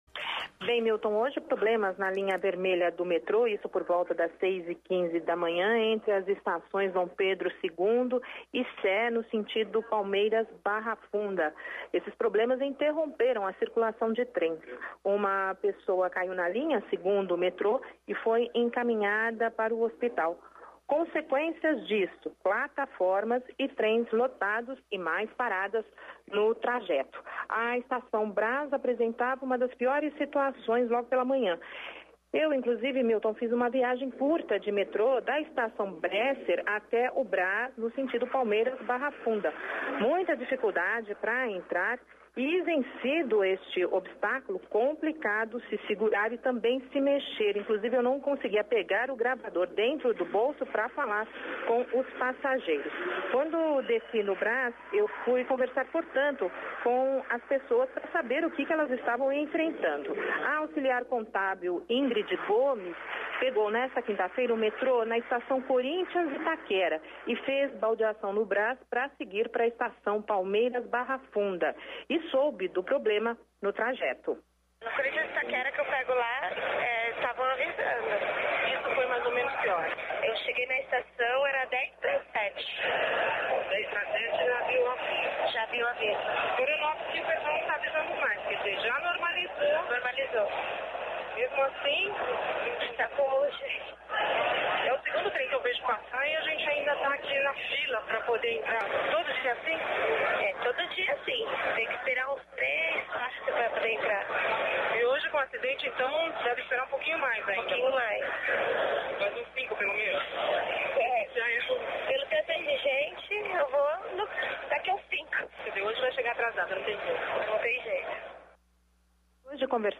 A viagem foi curta, até o Brás, mas suficiente para ouvir um monte de bronca dos passageiros, acostumados a passar por esta situação todos os dias. O Metrô alega que o problema foi maior porque às seis da manhã, a operação teve de ser paralisada para atender ocorrência na linha vermelha entre as estações Dom Pedro II e Praça da Sé